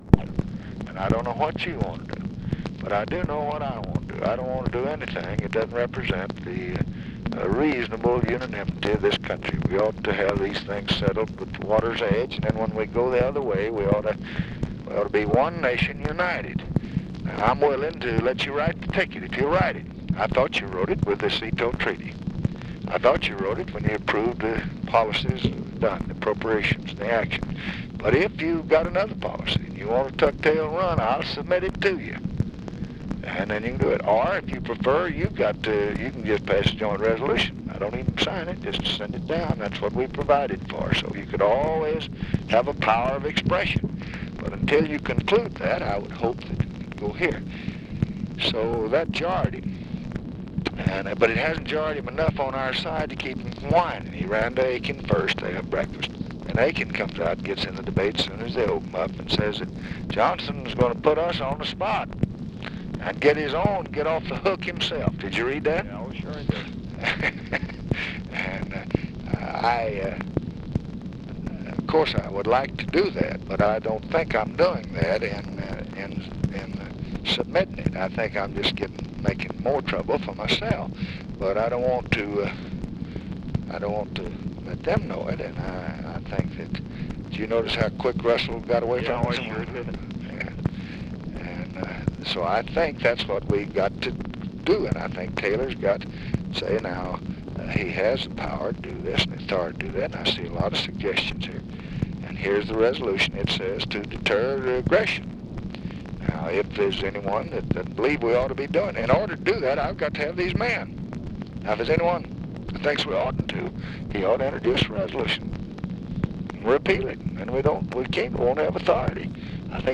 Conversation with ROBERT MCNAMARA, June 10, 1965
Secret White House Tapes